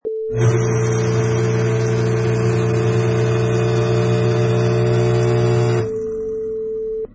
Wav files: Garbage Disposal 1
Garbage disposal grinds light refuse
Product Info: 48k 24bit Stereo
Category: Household / Kitchen - Garbage Disposals
Try preview above (pink tone added for copyright).
Garbage_Disposal_1.mp3